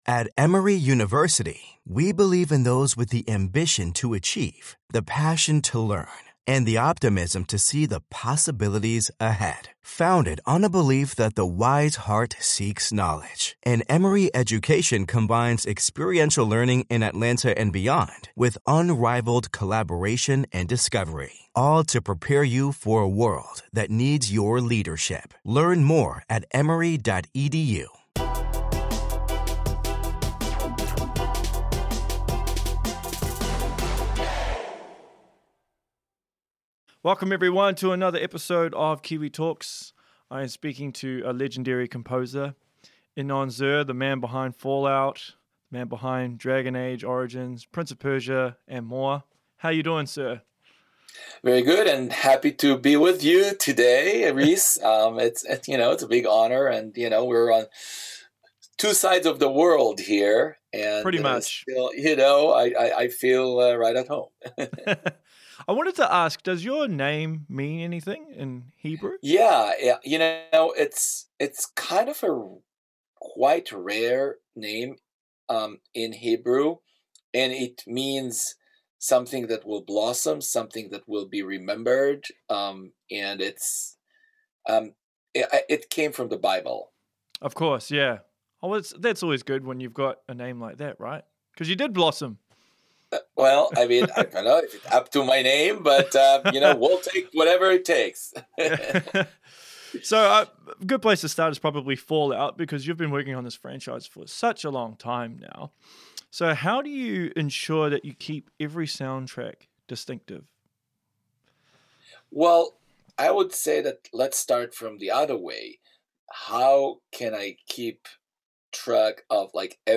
#91 - Inon Zur Interview (Fallout, Dragon Age:Origins, LOTR, Royalties, Bethesda, Film, Mixing etc.) ~ Kiwi Talkz Podcast